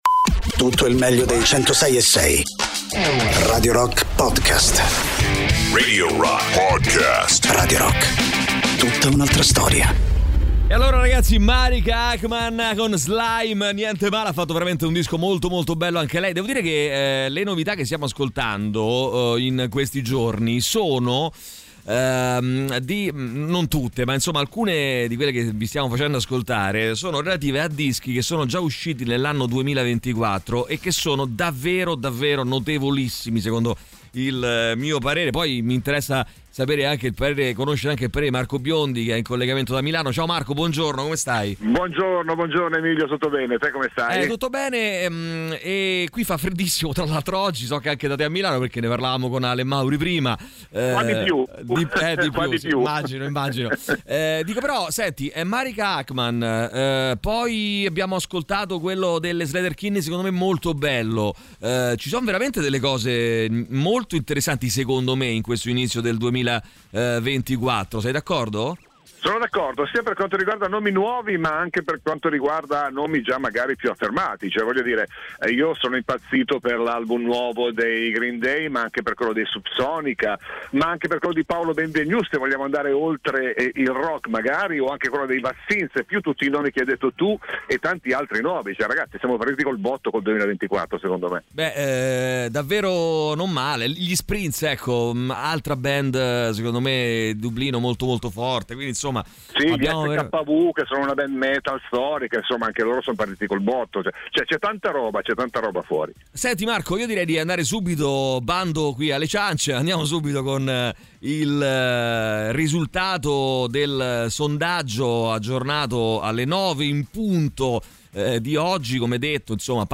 sui 106.6 di Radio Rock, presentano Unfamous.